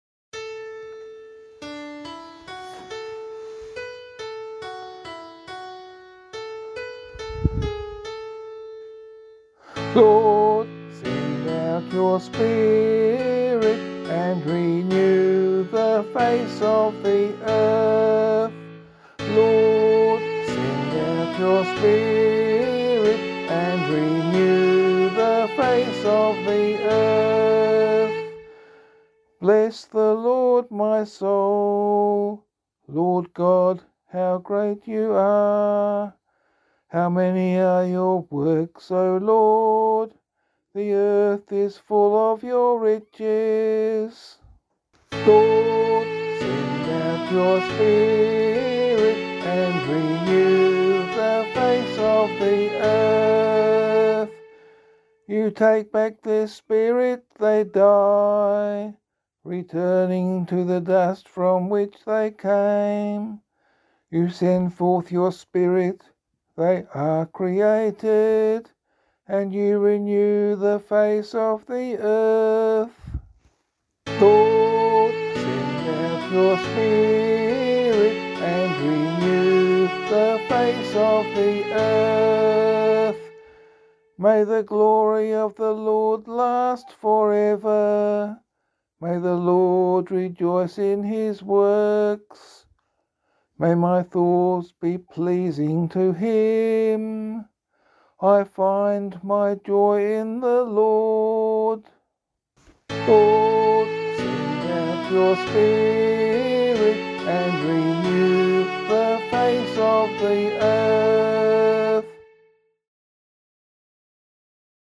032 Pentecost Day Psalm [LiturgyShare 8 - Oz] - vocal.mp3